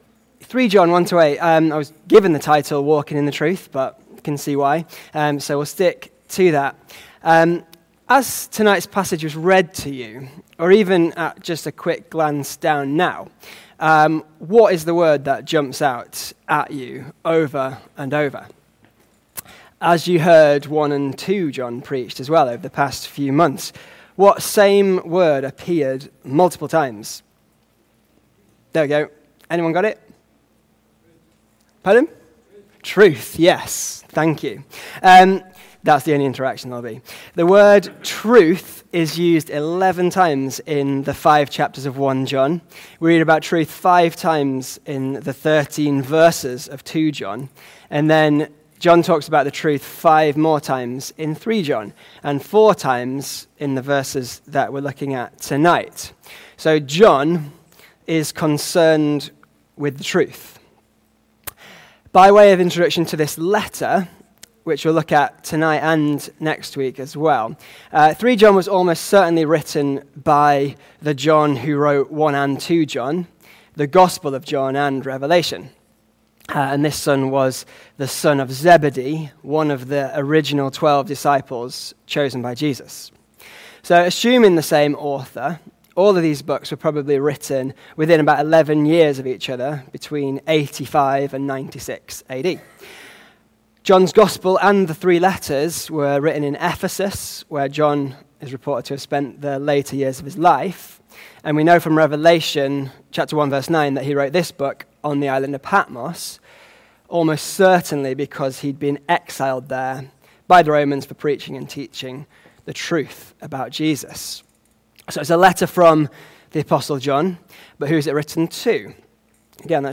Back to Sermons Walking in the truth